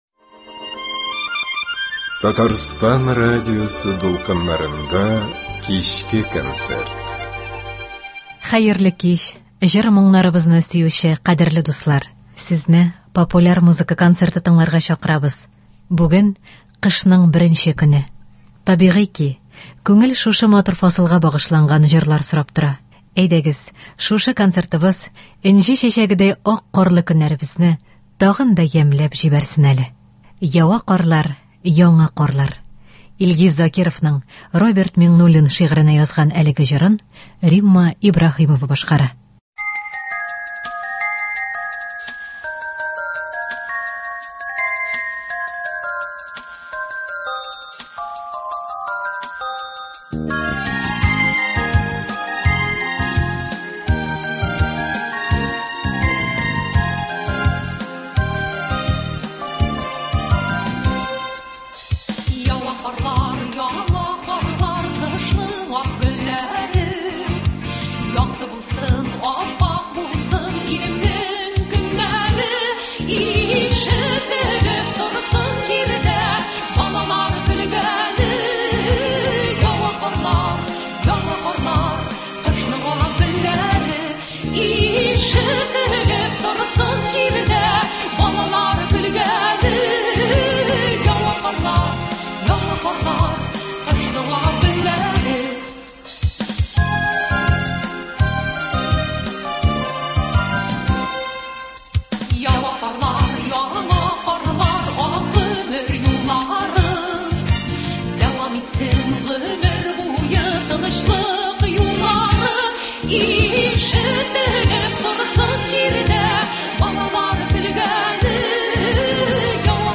Популяр музыка концерты.